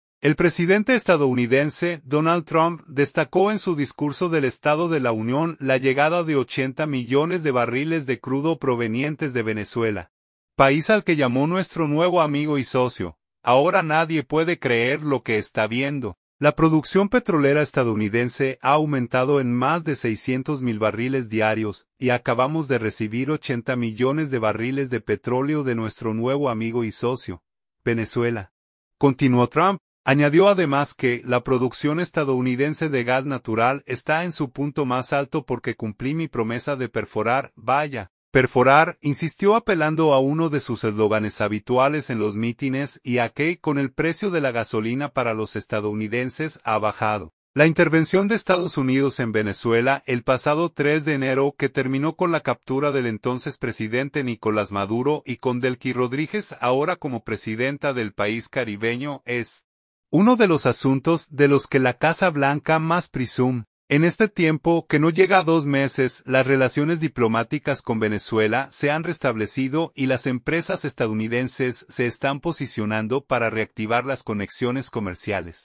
El presidente estadounidense, Donald Trump, destacó en su discurso del Estado de la Unión la llegada de 80 millones de barriles de crudo provenientes de Venezuela, país al que llamó "nuestro nuevo amigo y socio".